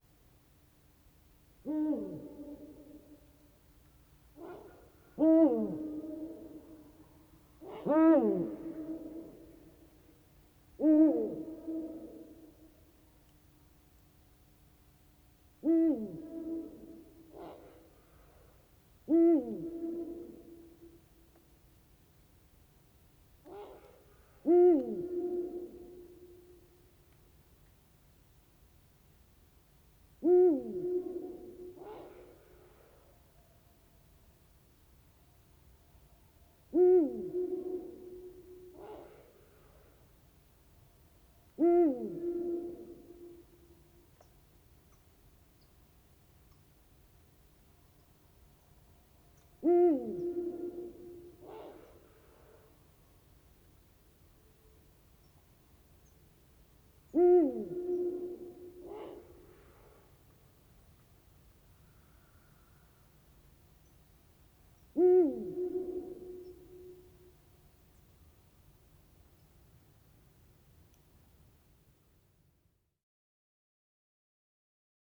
Eurasian Eagle-owl
When he passes just a few metres away, the movement of his echoes reveals the shape of the quarry walls. Each hoot consists of two notes slurred together, the first higher and louder than the second. The female responds with gruff rrrèh calls from somewhere down on the right.
27-Eurasian-Eagle-Owl-Hooting-Of-Male-And-Rrreh-Soliciting-Calls-Of-Female.wav